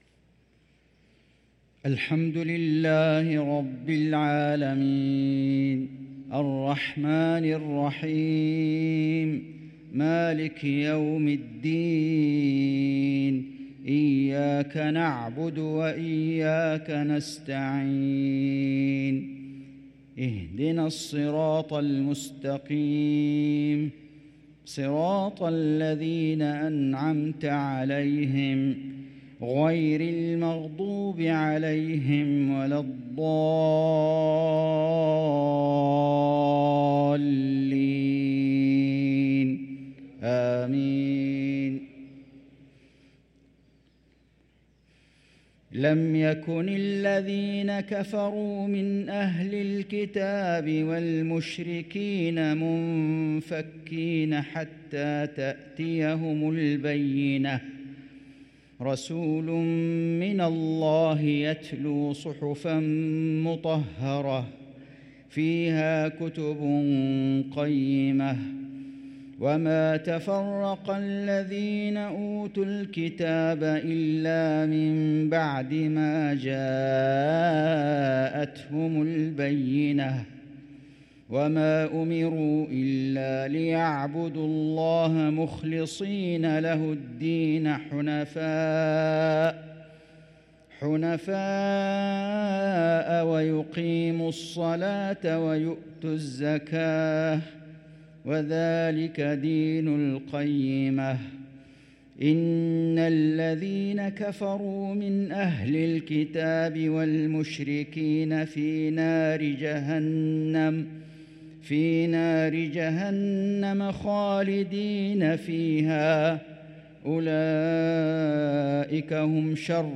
صلاة المغرب للقارئ فيصل غزاوي 16 صفر 1445 هـ
تِلَاوَات الْحَرَمَيْن .